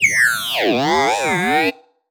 sci-fi_driod_robot_emote_06.wav